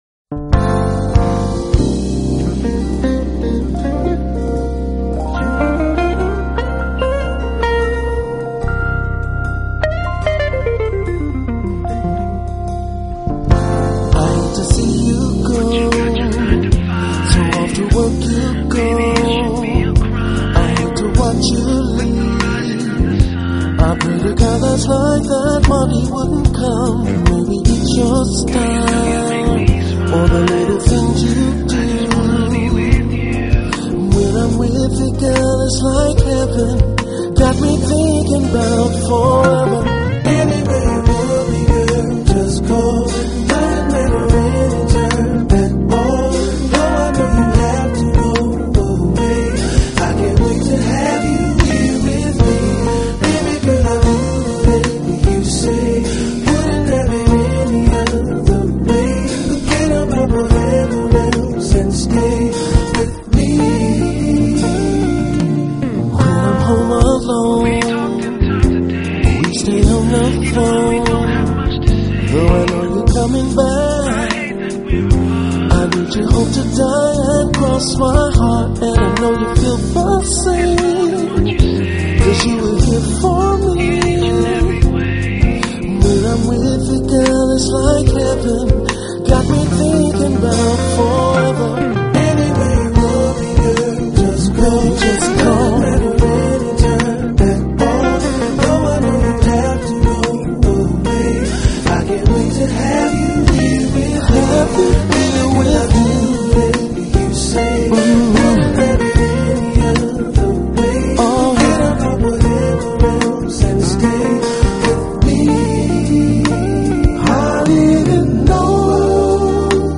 音乐类型：Smooth Jazz